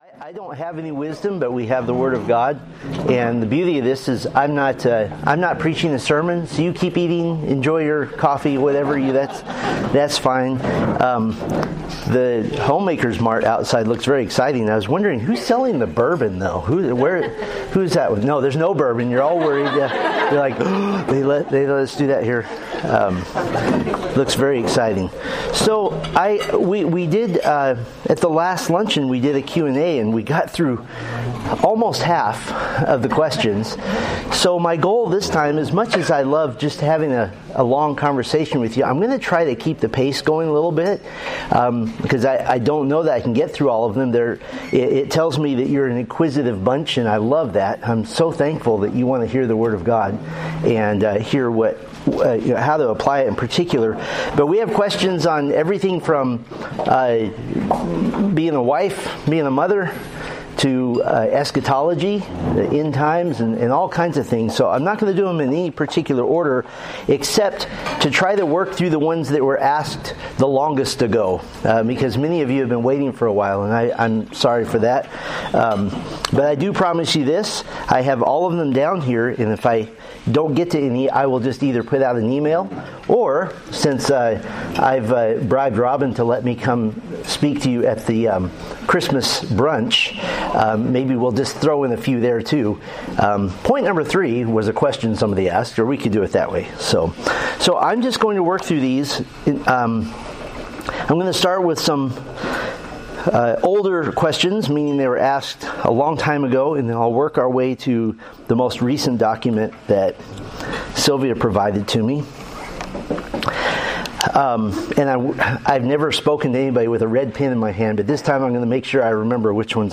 Date: Nov 8, 2025 Series: Growing in Grace Luncheons Grouping: Women's Ministry Events More: Download MP3